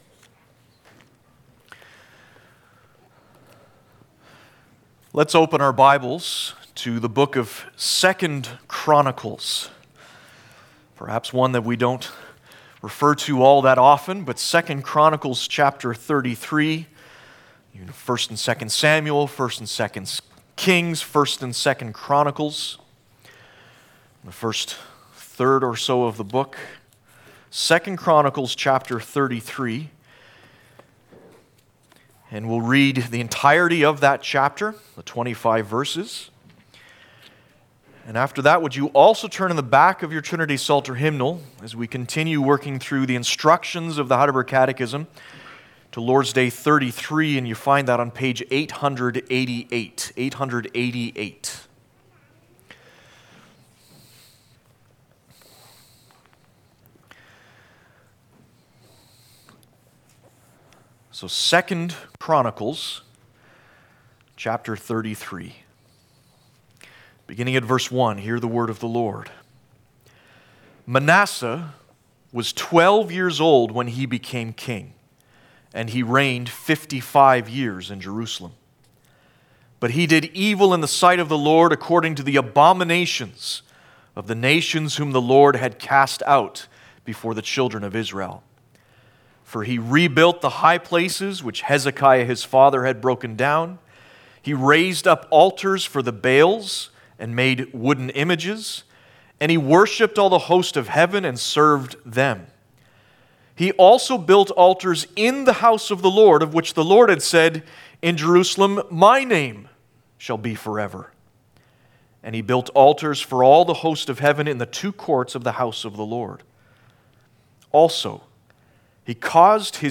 5.-Sermon-_-Converting-The-King.mp3